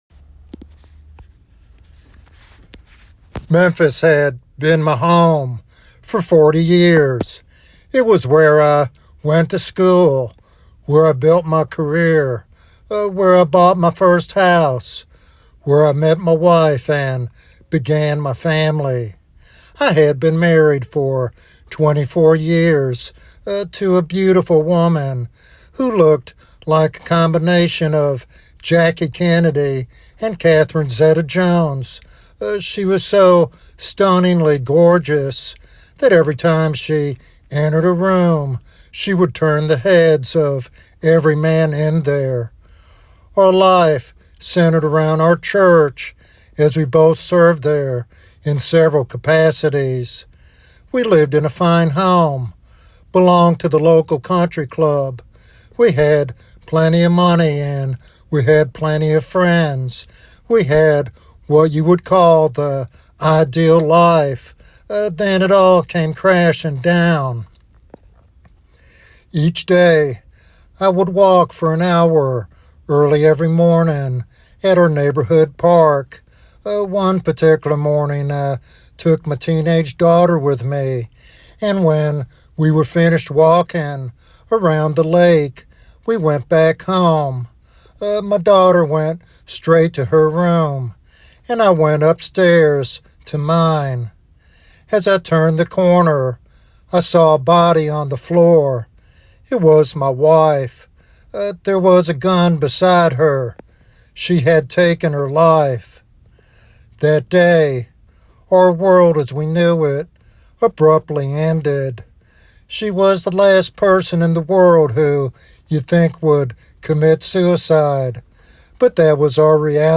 What is the main cause of suicide according to the sermon?